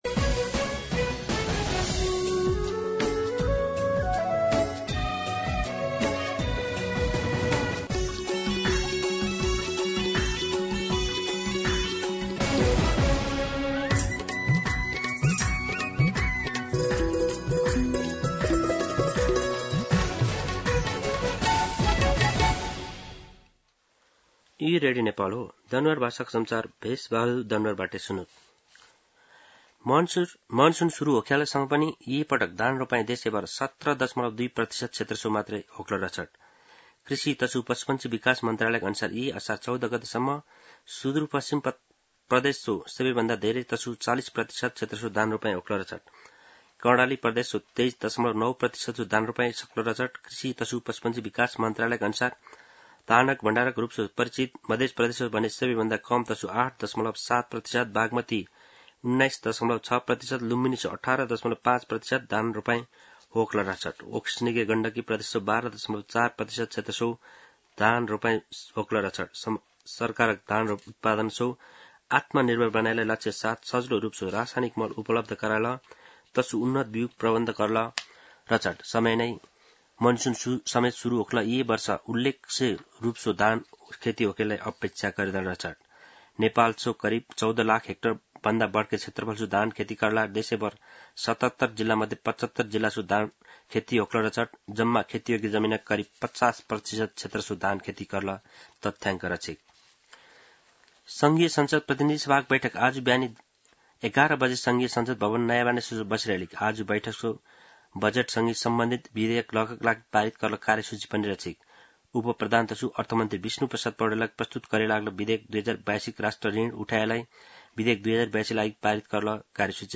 An online outlet of Nepal's national radio broadcaster
दनुवार भाषामा समाचार : १४ असार , २०८२
Danuwar-News-2.mp3